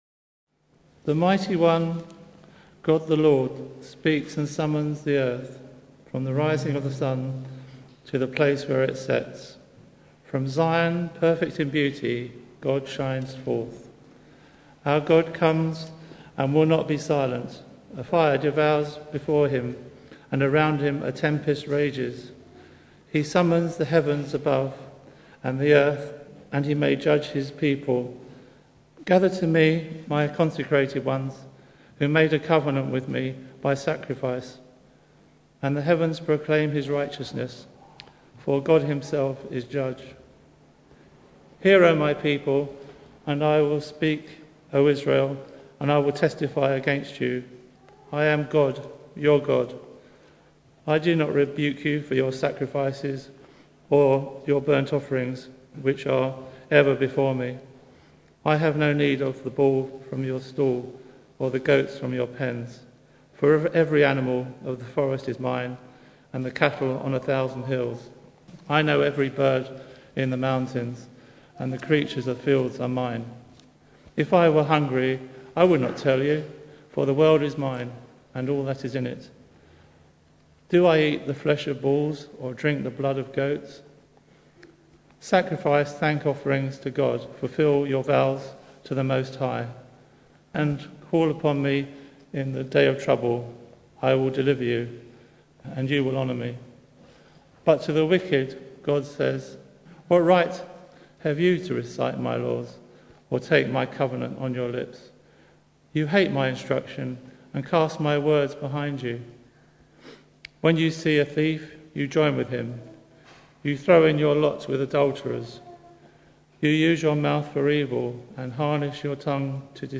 Psalm 50 Service Type: Sunday Morning Bible Text